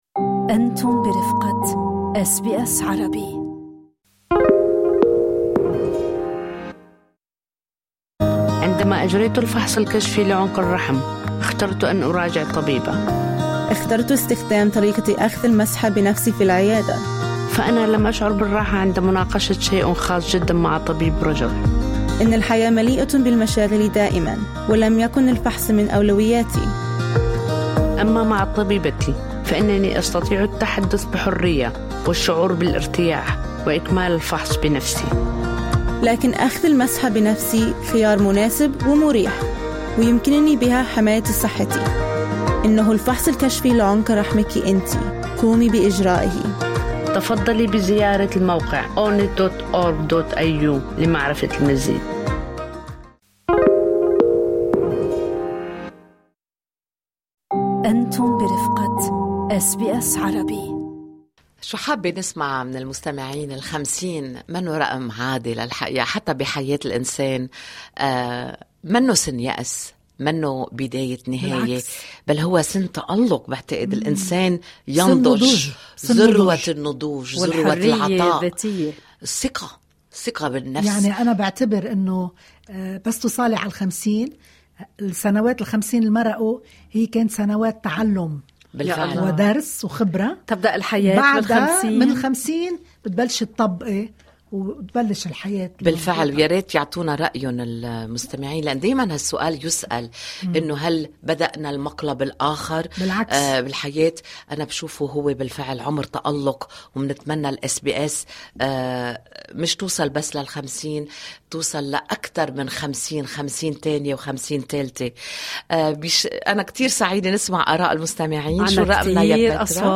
عبّر عدد من المتصلين من مختلف أنحاء البلاد عن ذكرياتهم مع الإذاعة، معتبرينها صلة وصل بالوطن الأم ومنبرًا ثقافيًا وإخباريًا حافظ على الهوية واللغة والانتماء.
اقرأ المزيد ترامب يُفعّل الخيار العسكري: انتشار مكثف للمارينز في لوس أنجلوس بصوت مفعم بالحنين، استرجع المستمعون أسماء مذيعين ومذيعات رافقوهم لعقود، وشاركوا قصصًا عن الاستماع للإذاعة في الطرقات، وفي المستشفيات، وفي لحظات الغربة الأولى.